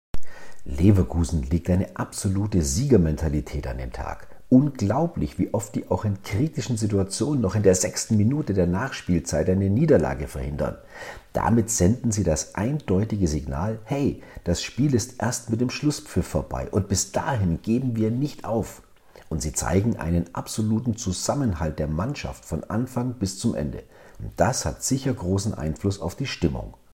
ehemaligem Leistungssportler, Mental- und Kommunikationscoach.